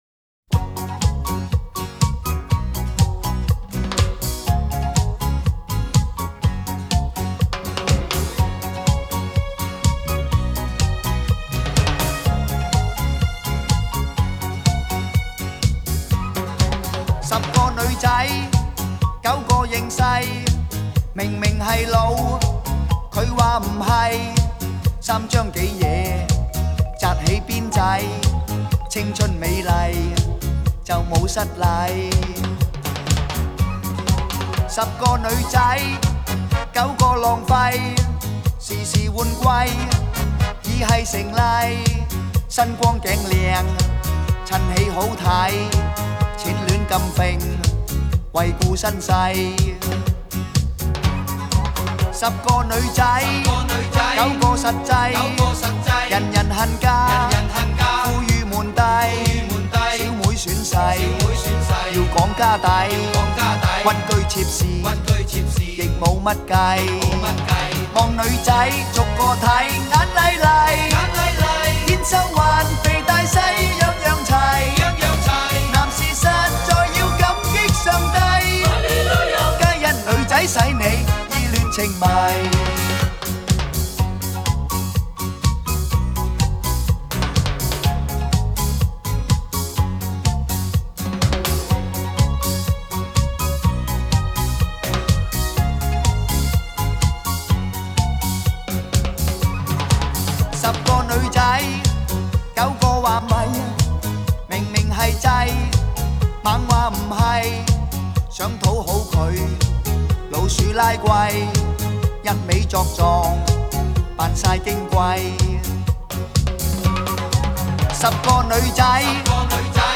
国风 收藏 下载